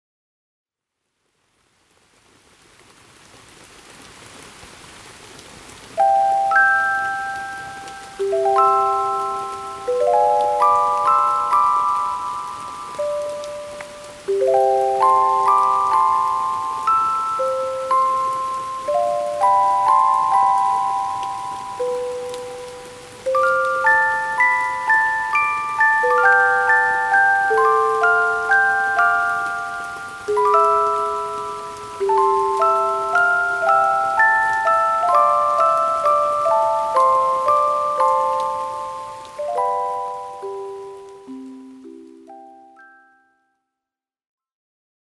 一定の周波数特性（1/fゆらぎ等）やテンポを持つ音（※８）が、過敏になった交感神経の緊張を緩和し、頭痛の頻度や不快感の軽減に寄与する可能性が示唆されています。